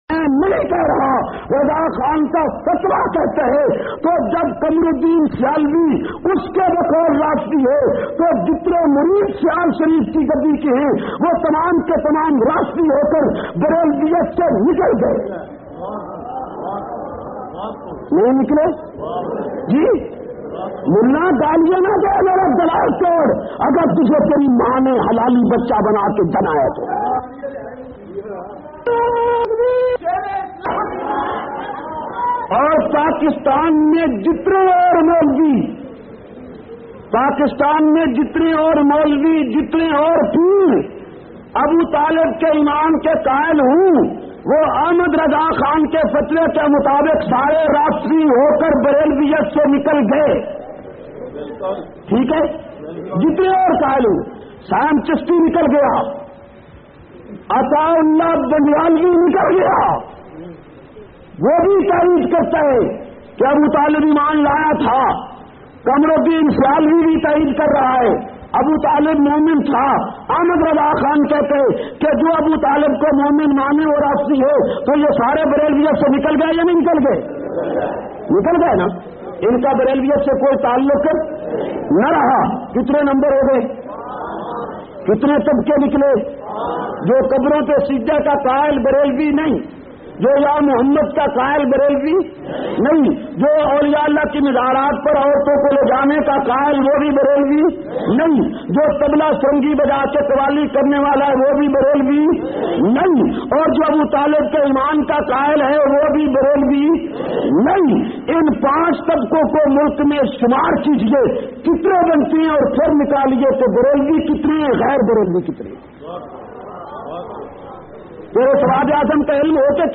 216- Muhammad ur Rasool ullah Conference kamalia.mp3